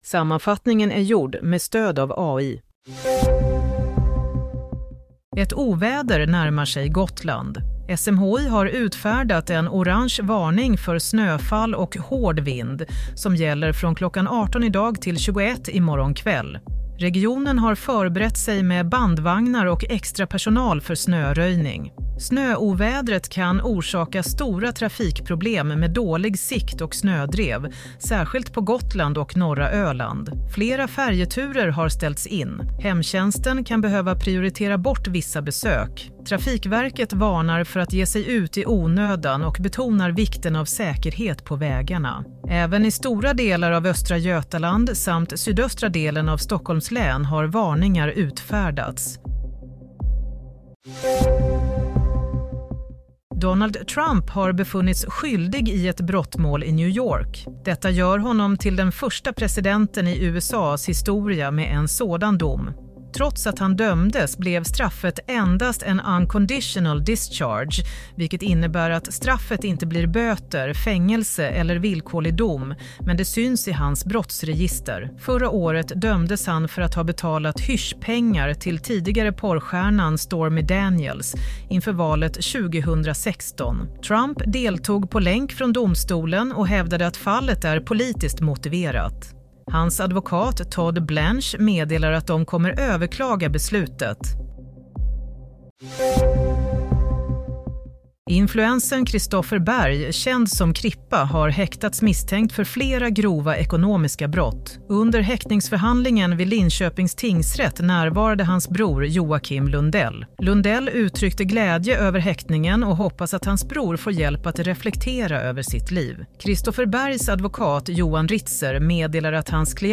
Nyhetssammanfattning – 10 januari 22:00